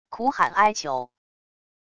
苦喊哀求wav音频